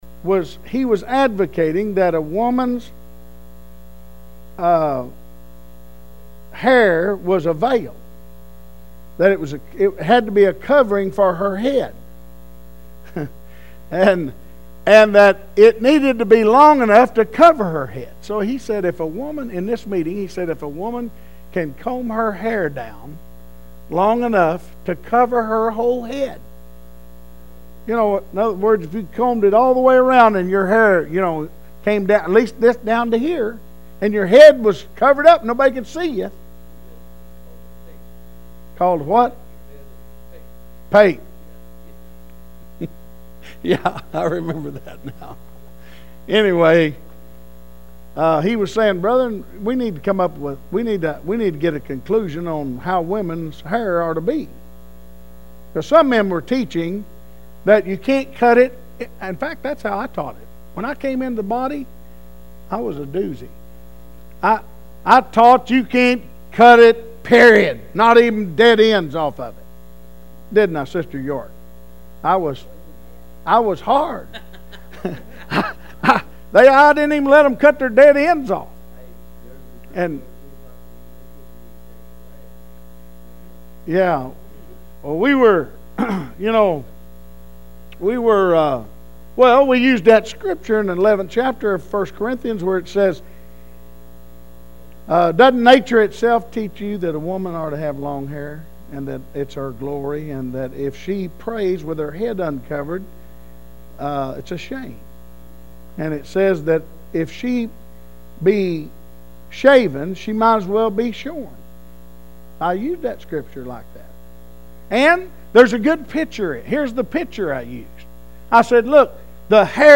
09/09/09 Wednesday Service